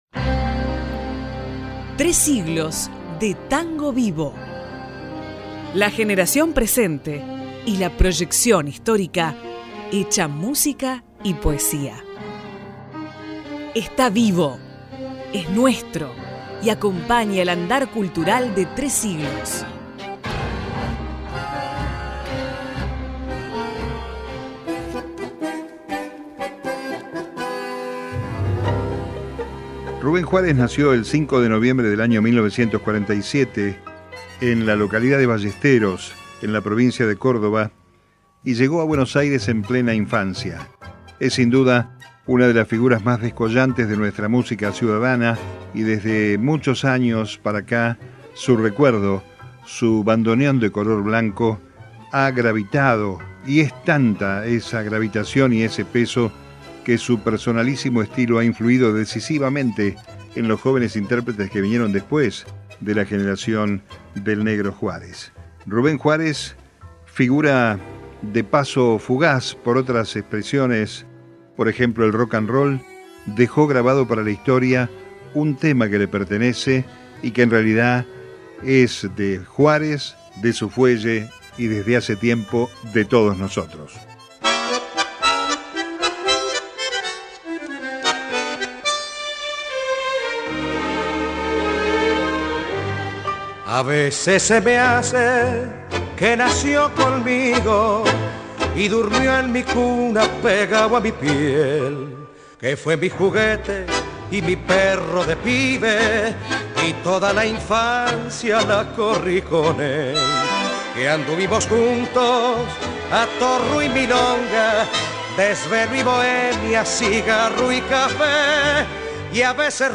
TANGO VIVOMúsica de tres siglos.